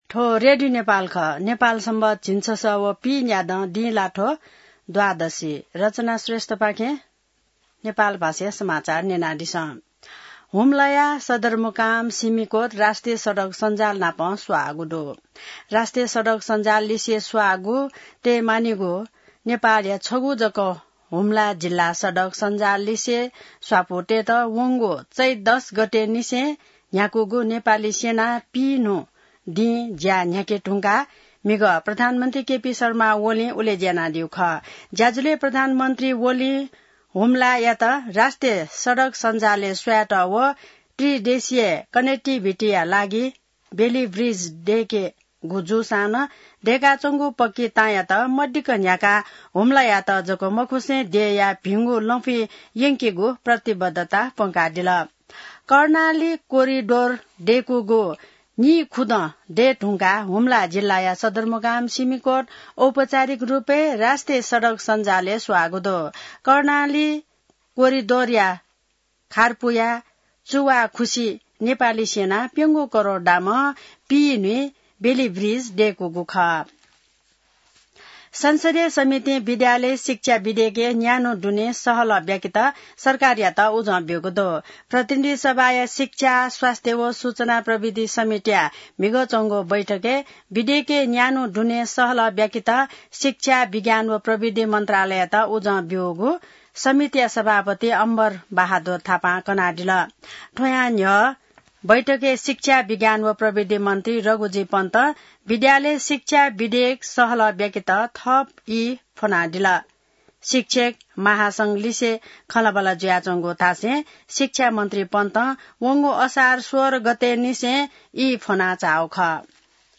नेपाल भाषामा समाचार : २३ असार , २०८२